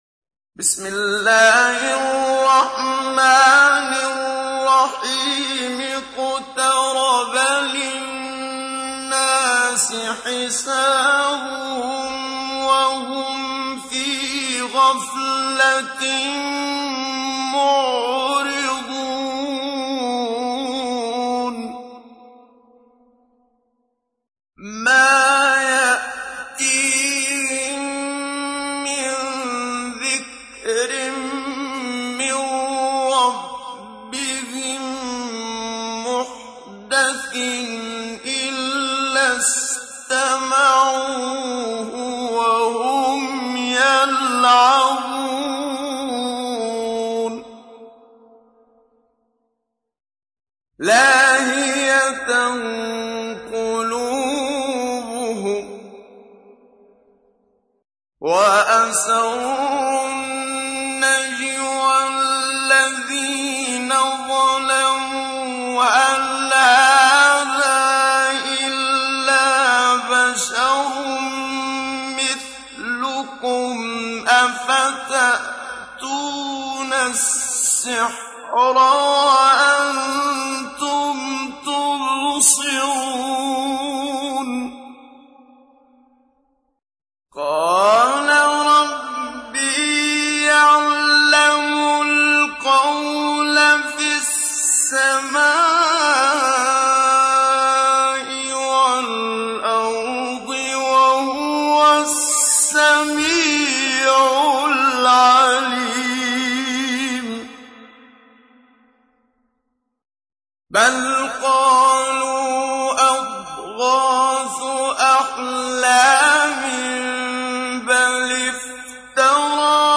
تحميل : 21. سورة الأنبياء / القارئ محمد صديق المنشاوي / القرآن الكريم / موقع يا حسين